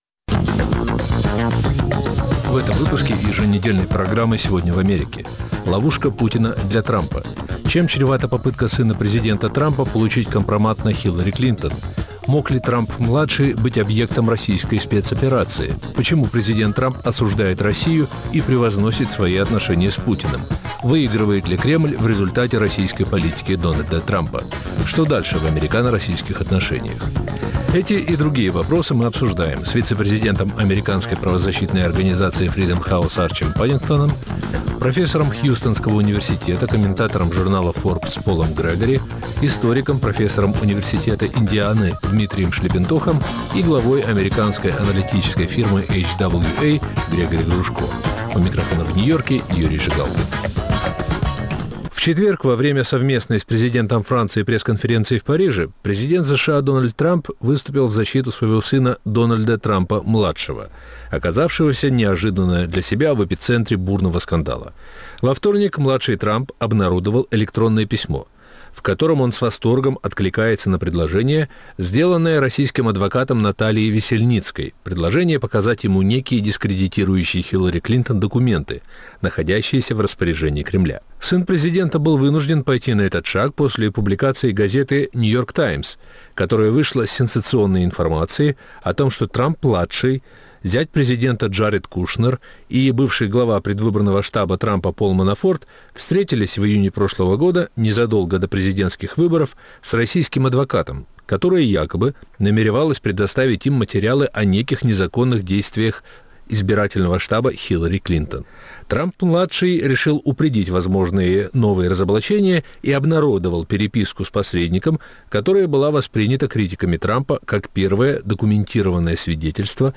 обсуждают американские эксперты